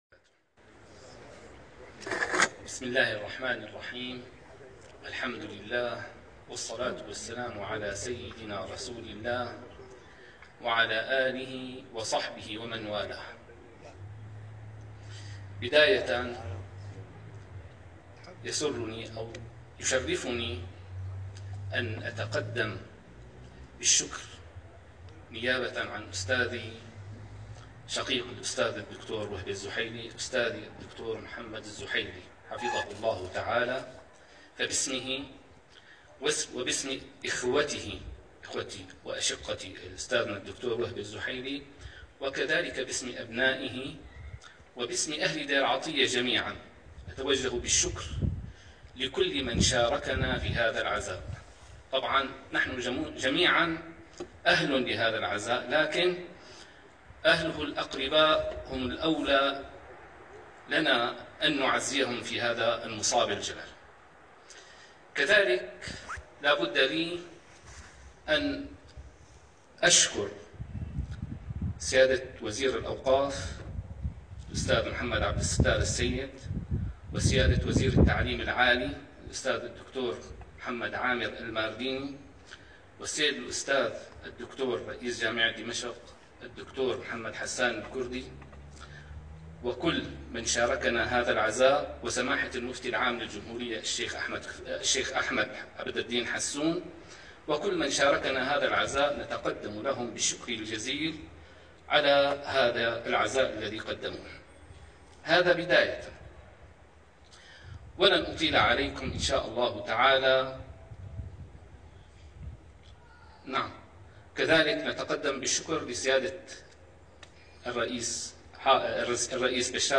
كلمة
في عزاء الشيخ وهبة الزحيلي